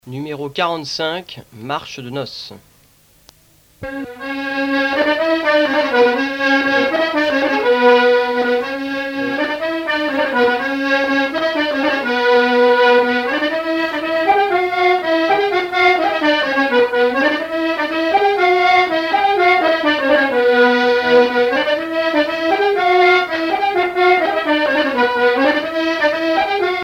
Marche de noce
circonstance : fiançaille, noce
Pièce musicale éditée